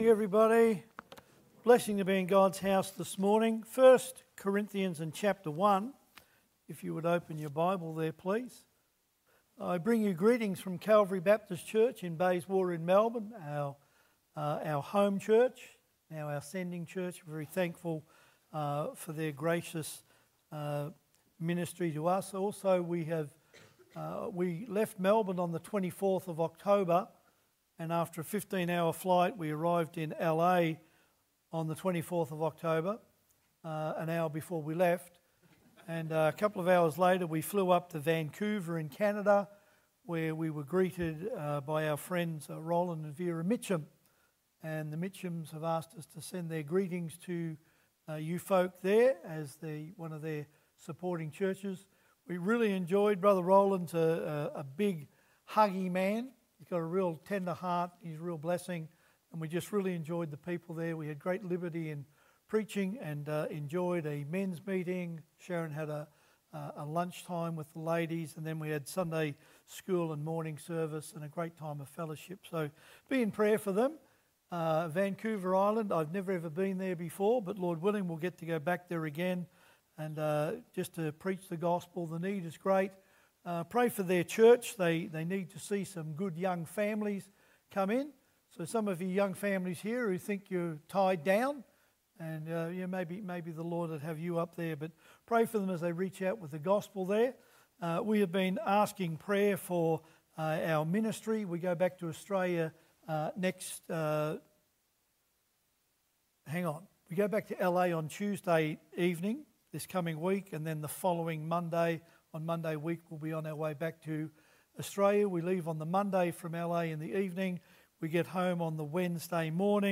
I Cor. 1:18-31 Service Type: Sunday AM « Follow the New Pattern By Whose Authority?